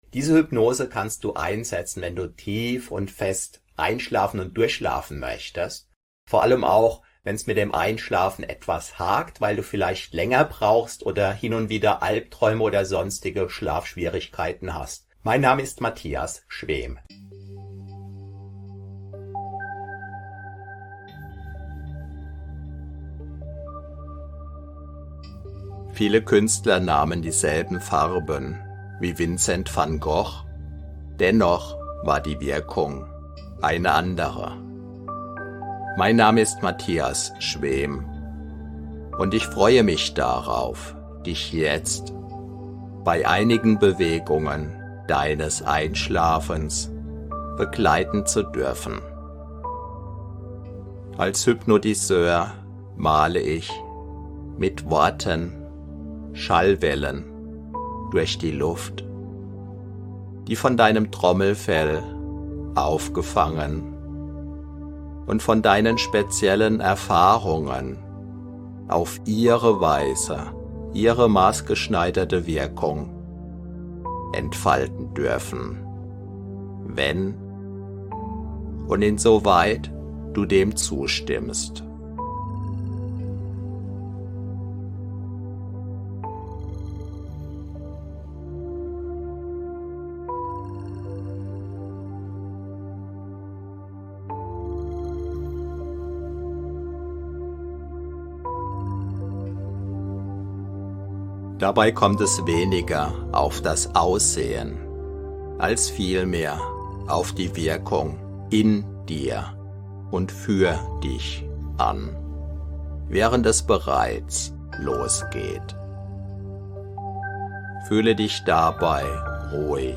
Mit vollen 60 Minuten kraftvoller Suggestionen hilft diese Hypnose deinem Unterbewusstsein, negative Gedanken und Ängste abzubauen, damit du voller Vertrauen ins Land der Träume gleiten kannst. Diese Hypnose wurde speziell entwickelt, um dich sanft zu unterstützen und dir zu helfen, eine tiefere Entspannung zu finden.